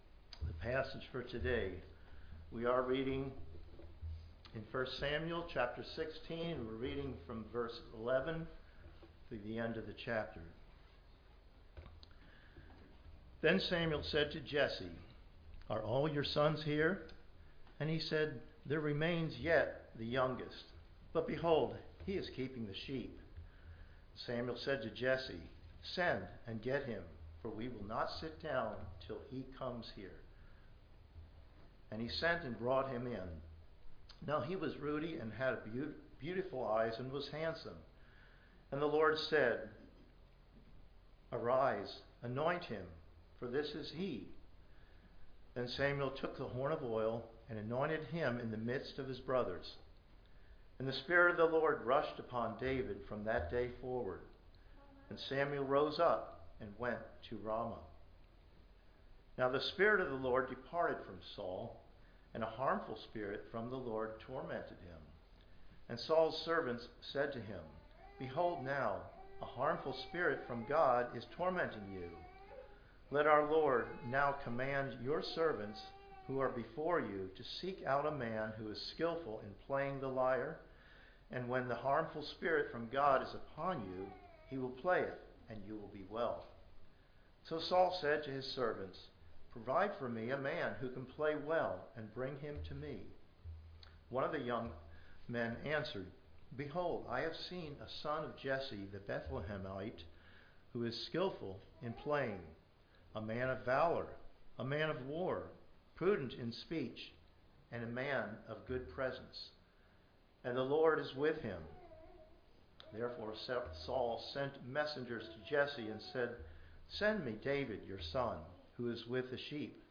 Passage: 1 Samuel 16:11-23 Service Type: Sunday Morning Worship